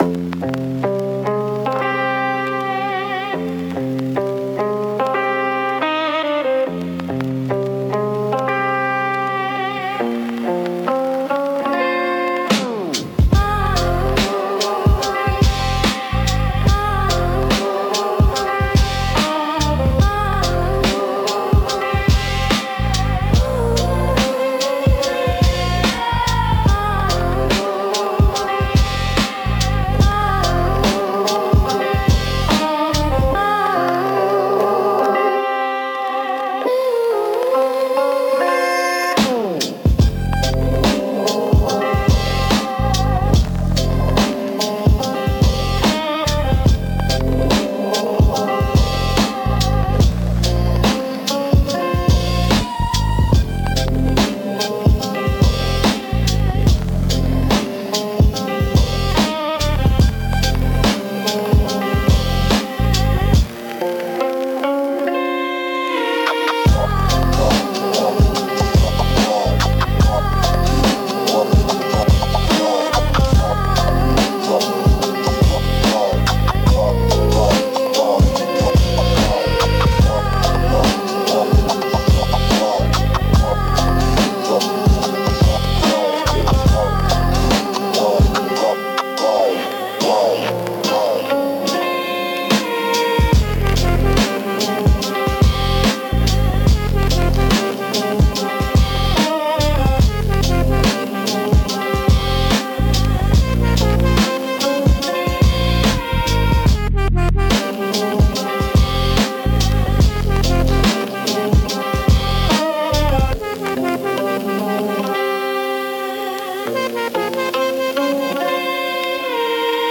Instrumental - The Weight of Late Nights